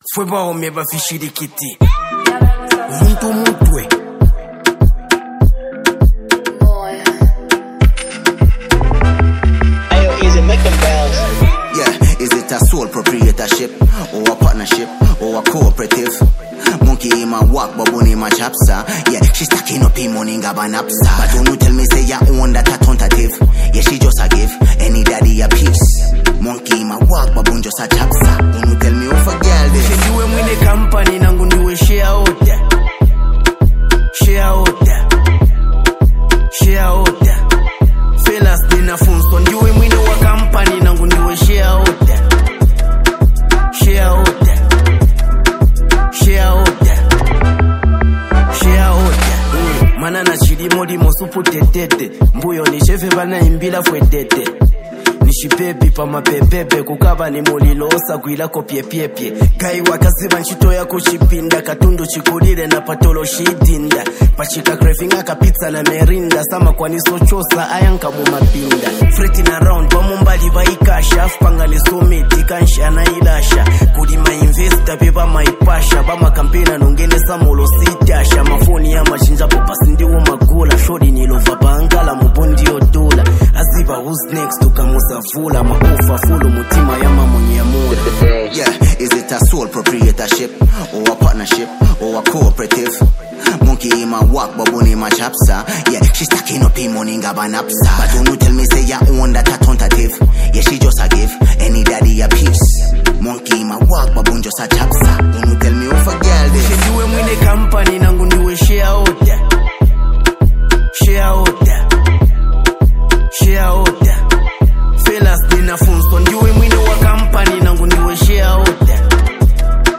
This song has a vibe of confidence and ambition
The beat is infectious